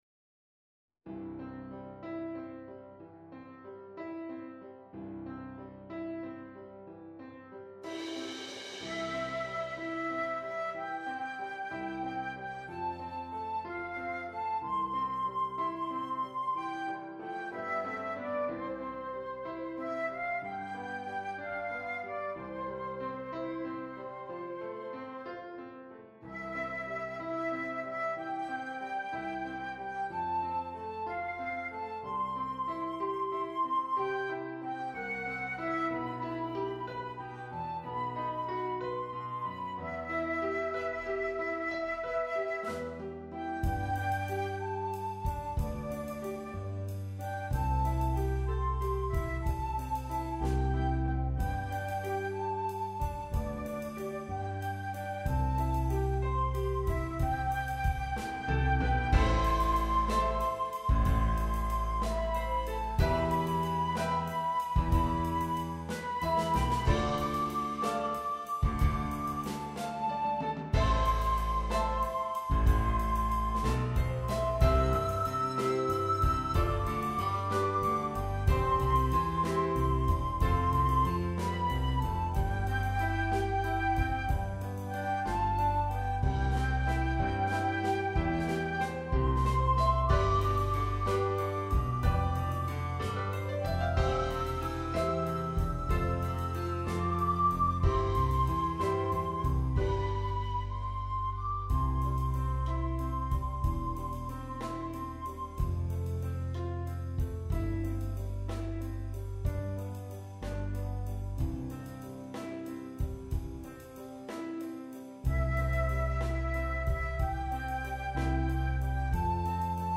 traditional Christmas Carol
Hymns